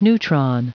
Prononciation du mot neutron en anglais (fichier audio)
Prononciation du mot : neutron